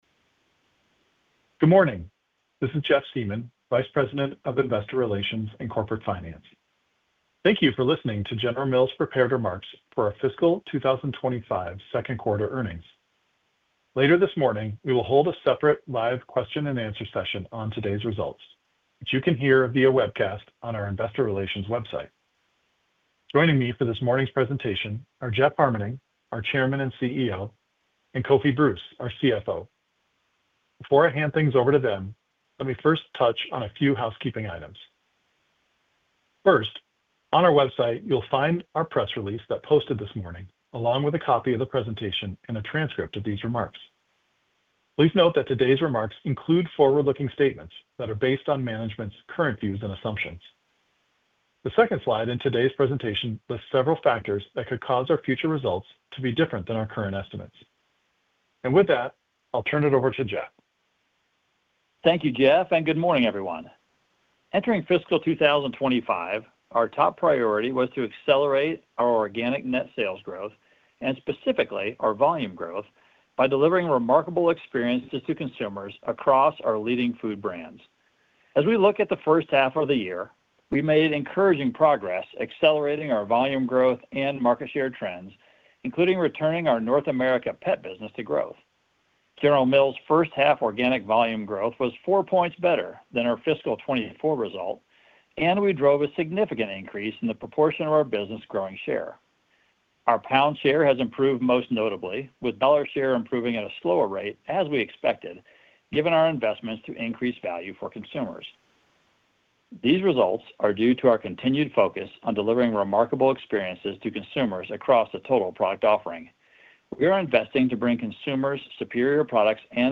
Recording - Prepared Remarks (opens in new window)